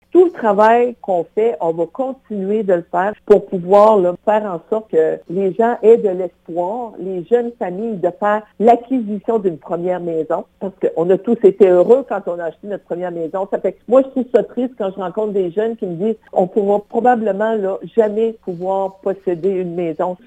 D’ici le scrutin, la salle des nouvelles de CFIM vous présente des entrevues thématiques réalisées chaque semaine avec les candidat(e)s. Les sujets abordés sont les suivants: Environnement (31 mars au 2 avril), Territoire et insularité (7 au 9 avril) et Perspectives économiques (14 au 16 avril).